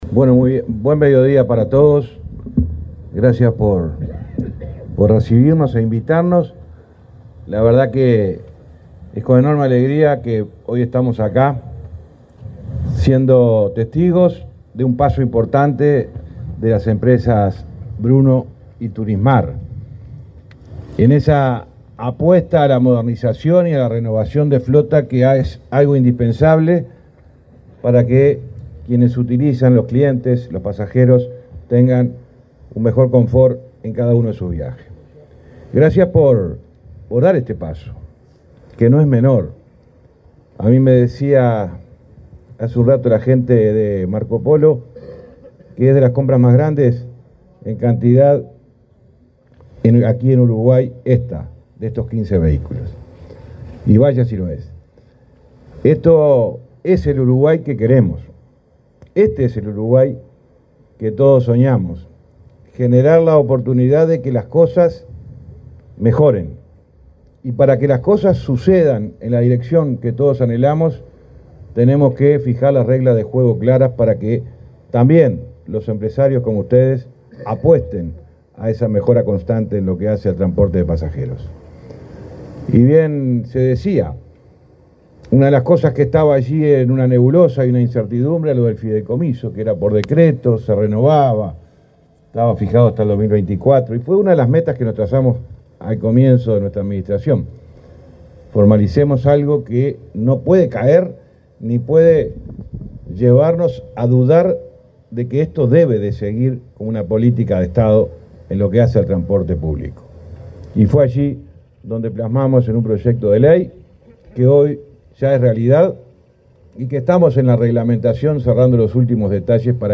Palabras del ministro de Transporte, José Luis Falero
Palabras del ministro de Transporte, José Luis Falero 14/10/2024 Compartir Facebook X Copiar enlace WhatsApp LinkedIn Este lunes 14, el ministro de Transporte, José Luis Falero, hizo uso de la palabra en el acto de presentación de unidades de transporte de las empresas Bruno y Turismar, realizado en la Quinta de Arteaga, en Montevideo.